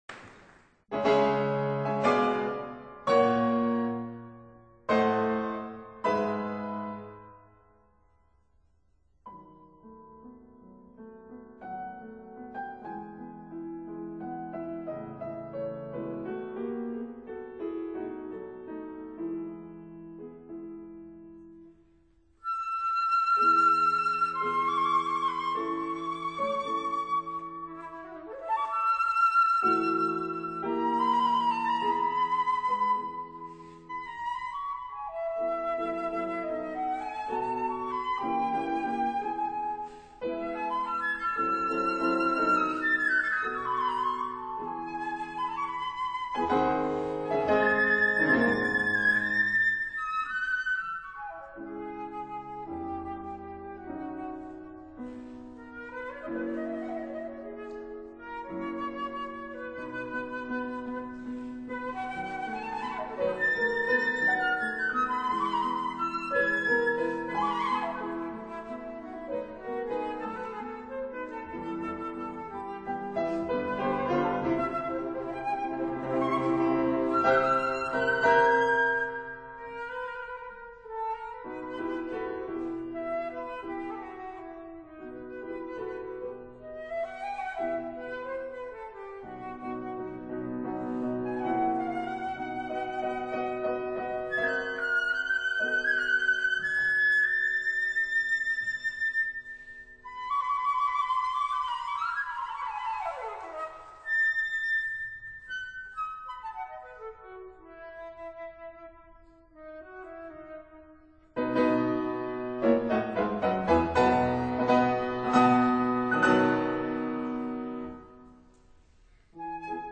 Instrumental
Andras Adorjan, Flute
Piano